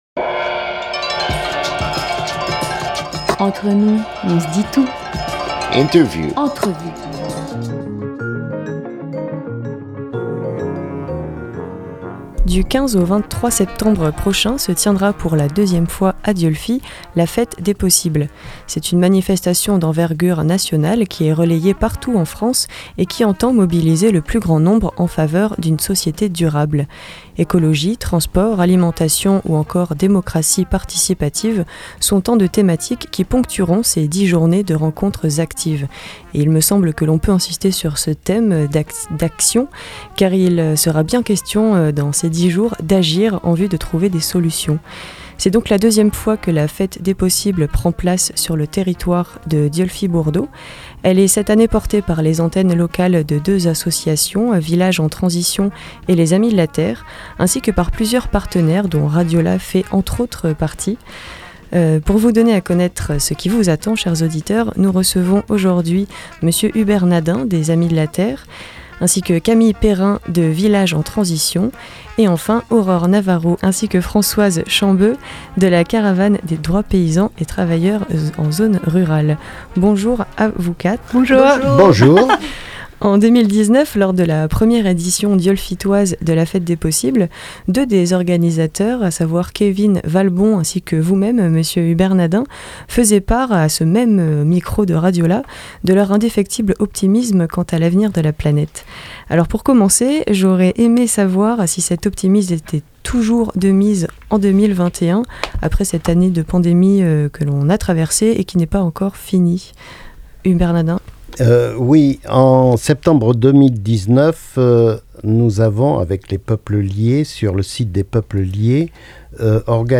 13 septembre 2021 8:00 | Interview